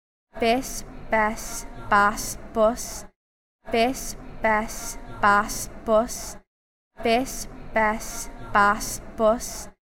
BIT BET BAT BUT (non-local Dublin speaker)
BIT_BET_BAT_BUT_(non-local_speaker).mp3